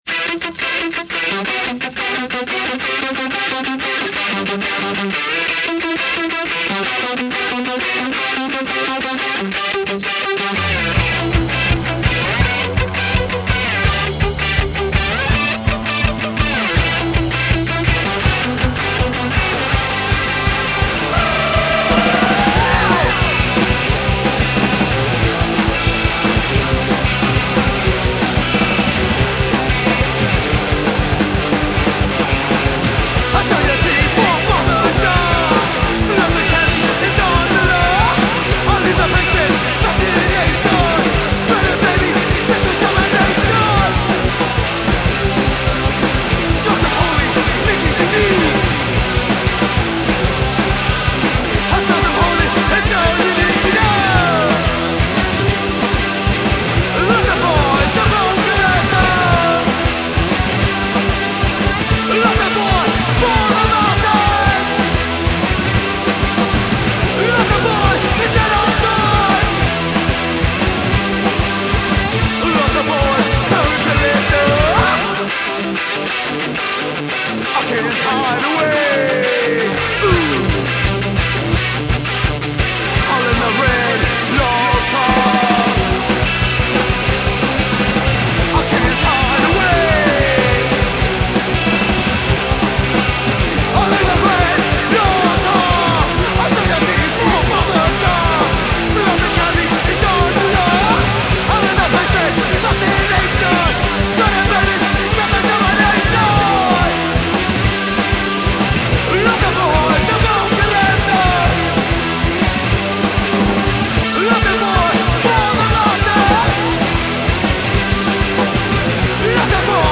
Deranged and violent Post-PUNK from New York City.